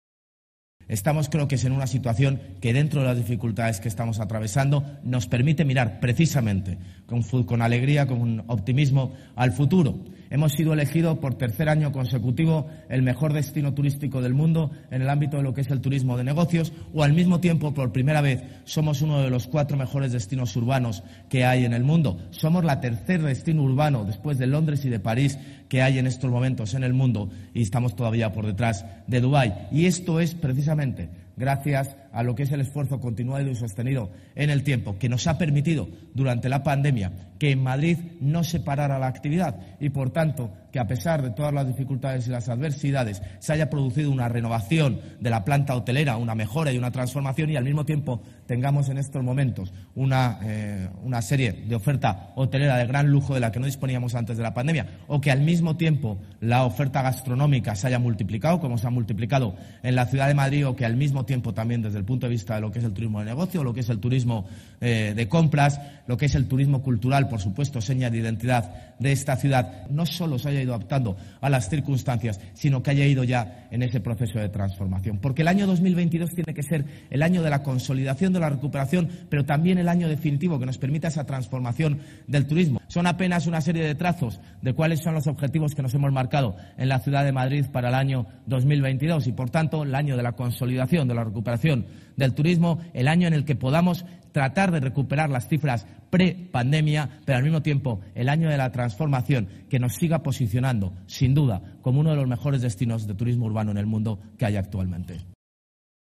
Palabras que Almeida ha pronunciado en la inauguración del VIII Foro de Innovación Turística que organiza el Grupo Hotusa en la antesala de la Feria Internacional de Turismo (FITUR), a la que ha asistido también la concejala delegada
JLMartinezAlmeida-HotusaForoInnovacionTuristica-17-01.mp3